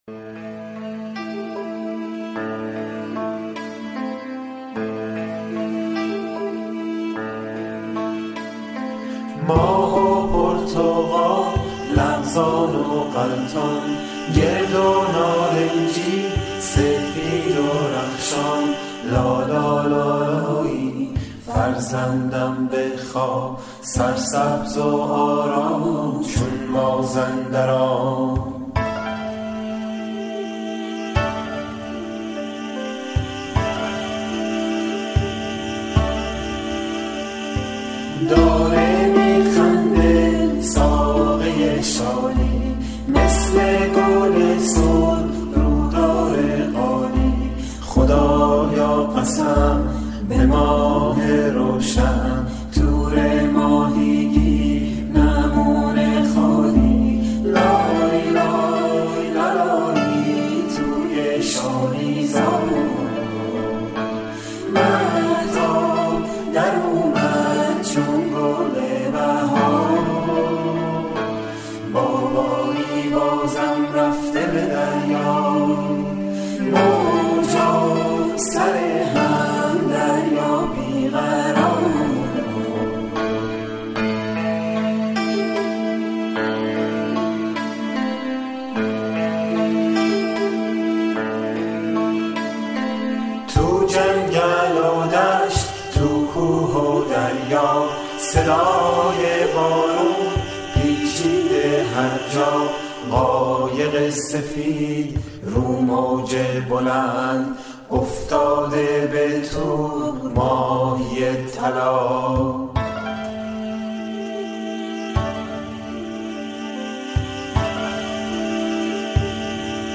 لالایی